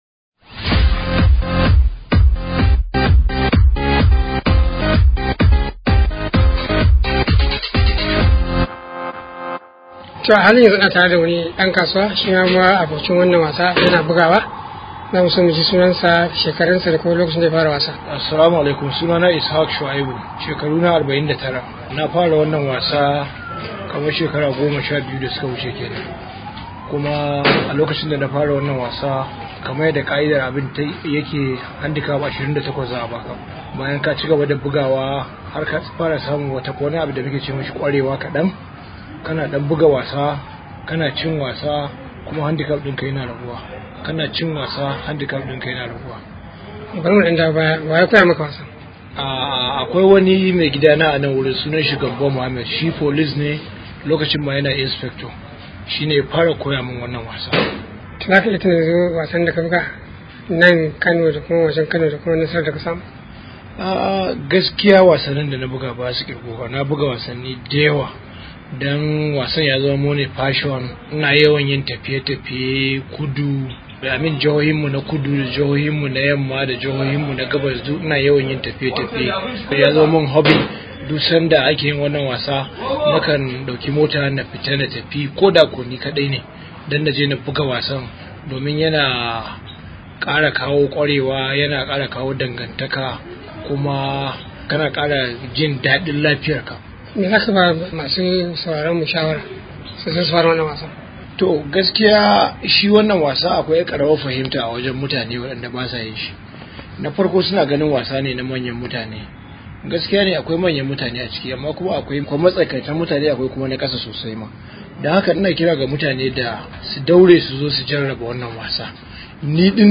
Daga filin wasan kwallon Golf na Kano Club